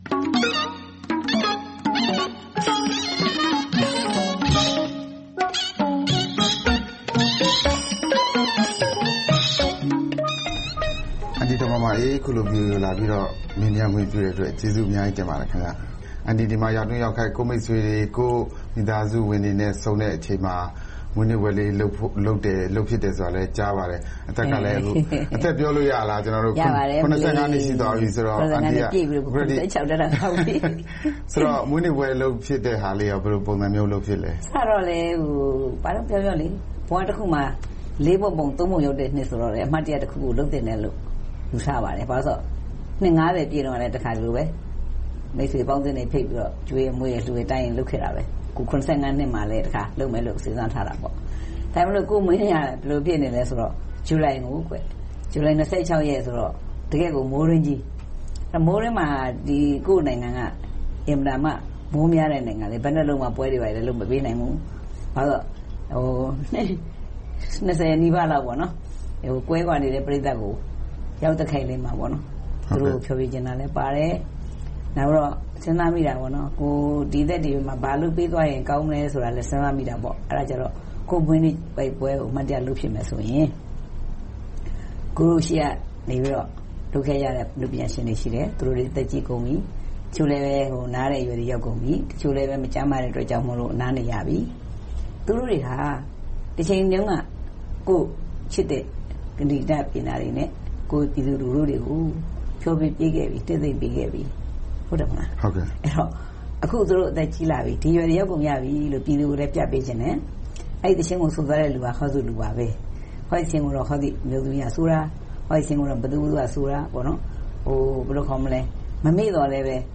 ဒေါ်မာမာအေးကို တွေ့ဆုံမေးမြန်းထားပါတယ်။